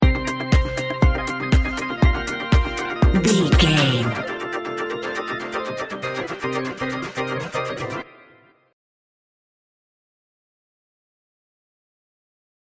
Aeolian/Minor
uplifting
futuristic
energetic
repetitive
bouncy
funky
electric piano
synthesiser
drum machine
electronic
techno
synth lead
synth bass